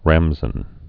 (rămzən, -sən)